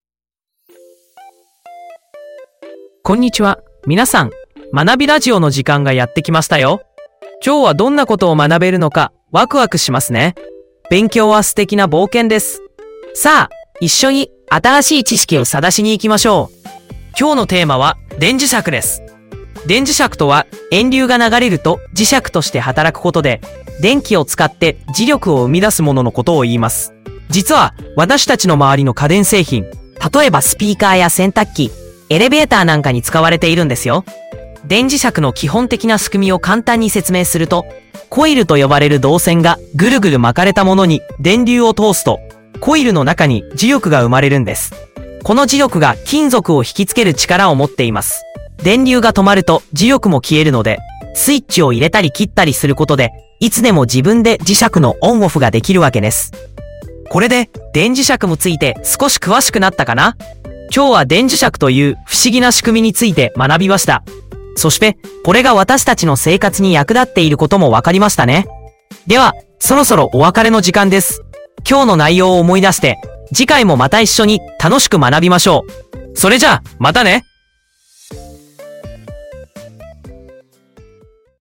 まなびラジオ」は、ユーザーがテーマを入力するだけで、生成AIがそのテーマに沿った読み上げ原稿とラジオ形式の音声メディアを自動生成します。